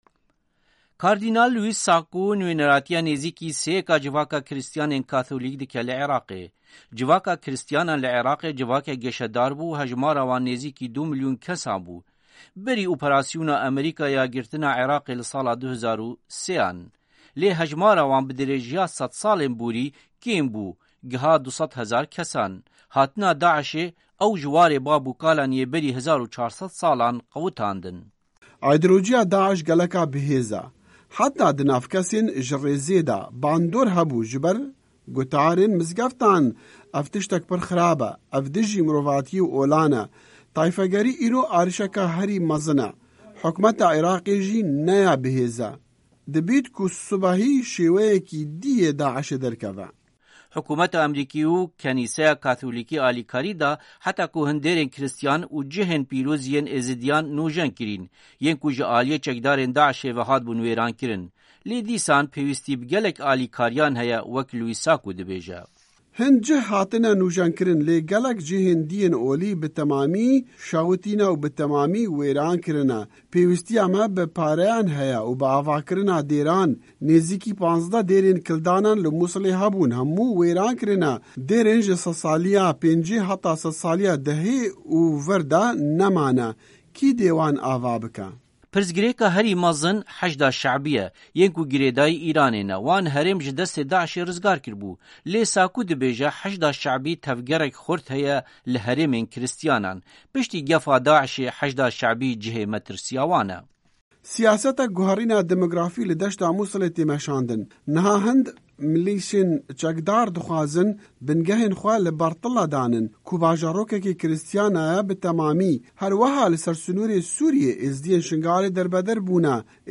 Raporta Taybet li Ser Civîna Azadiya Olan-Washington